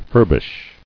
[fur·bish]